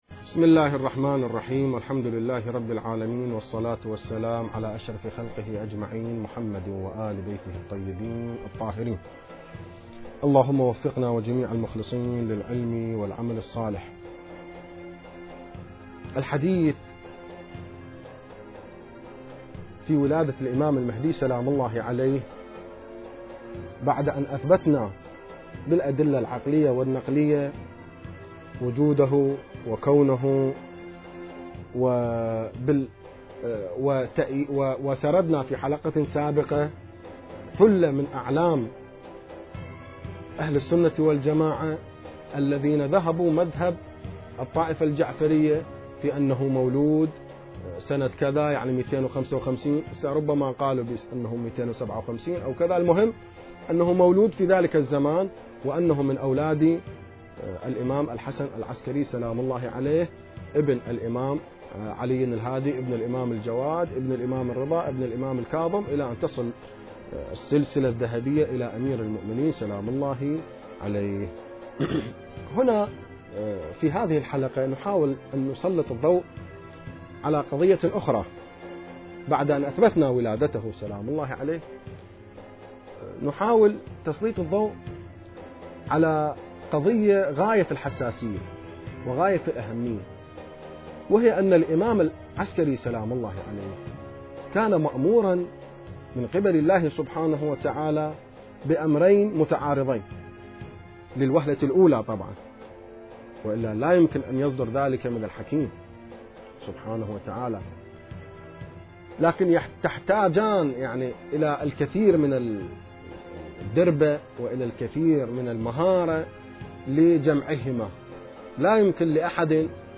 المكان: اذاعة الفرات